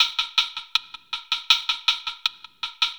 Ambient Wood 02.wav